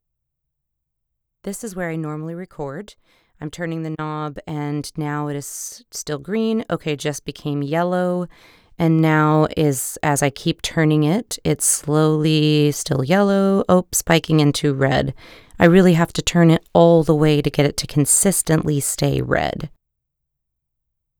That is distortion where the digital system stopped following your voice because it was too loud.
So just to be sure I understand- where I had the knob turned at about 10 seconds (about 4:00 on my dial) would be the recommended setting- Mostly green with just spikes into yellow- and no red.